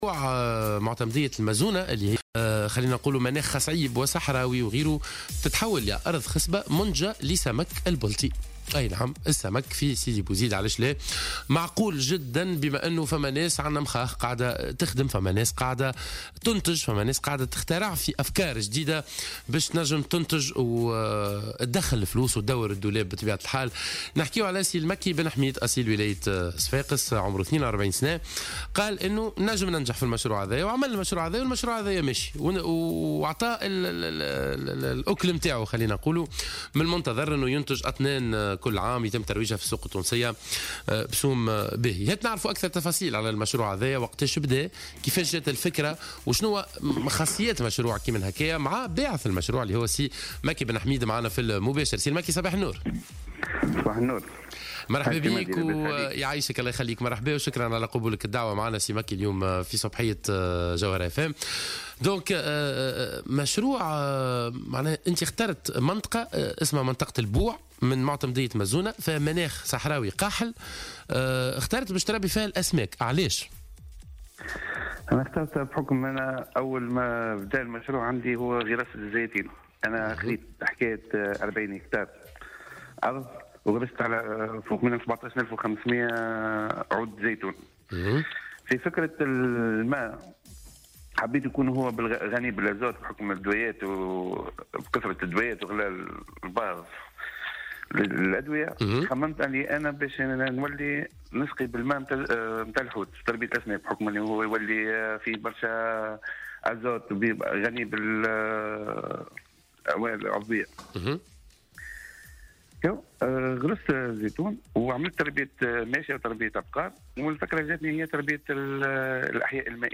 في اتصال مع "صباح الورد" على "الجوهرة اف أم"